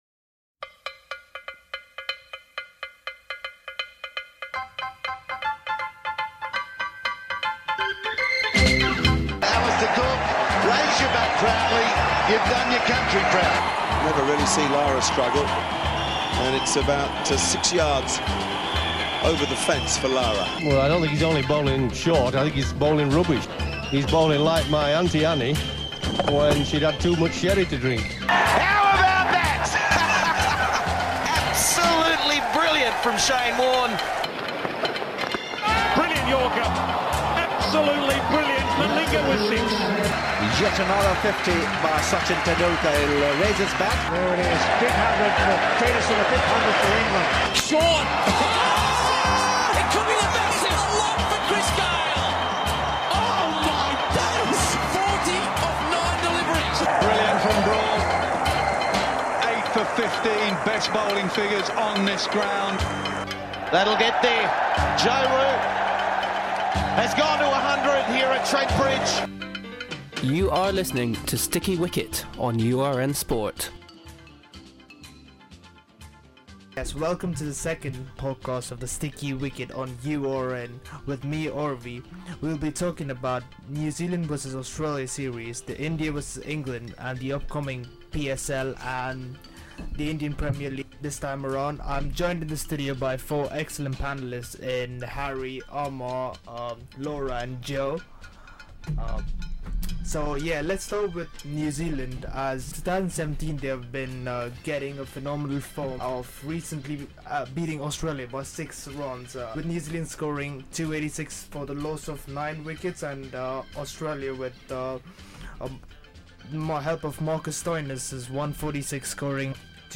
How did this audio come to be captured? is joined in the studio